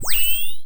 sci-fi_power_up_01.wav